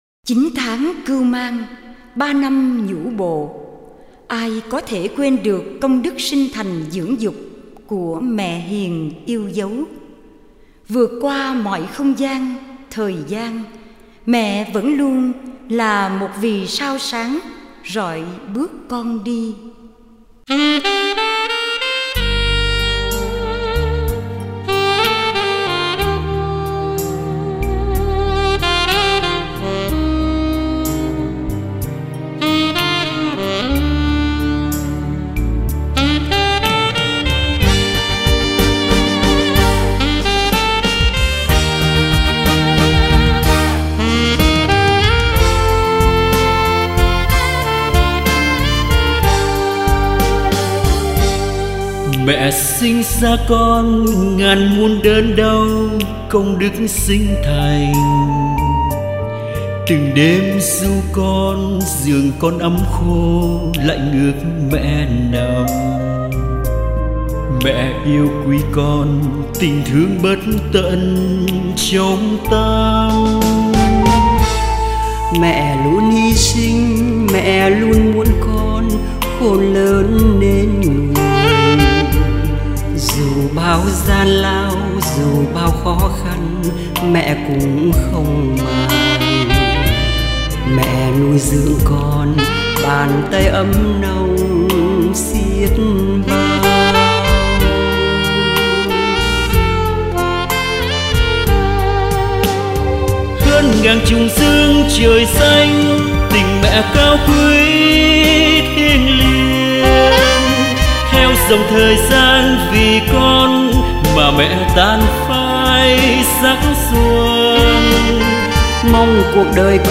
Tân Nhạc